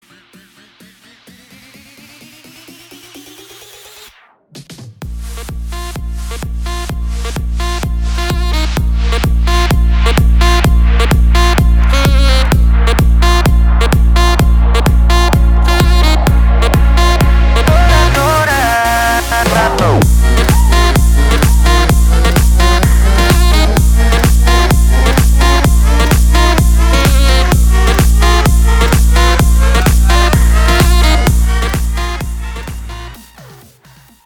• Качество: 320, Stereo
громкие
зажигательные
веселые
Саксофон
Moombahton
Big Room
electro house